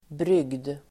Ladda ner uttalet
Uttal: [bryg:d]